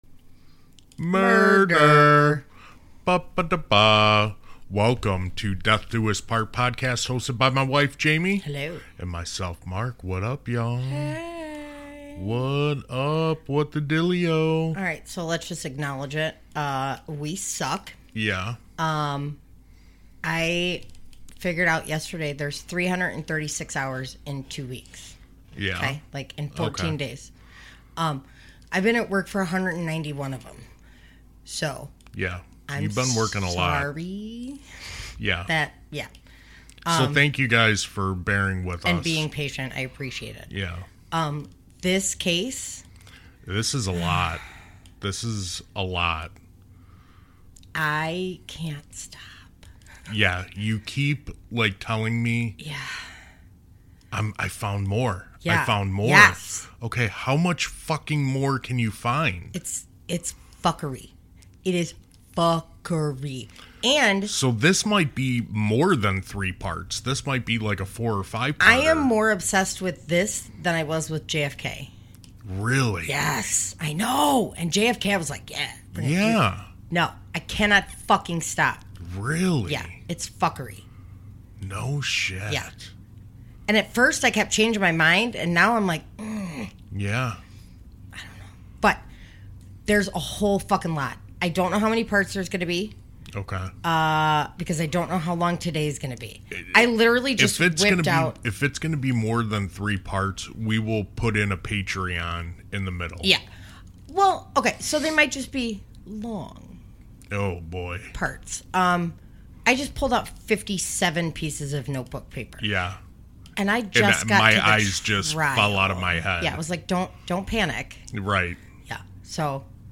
True crime with a cop husband and paramedic wife.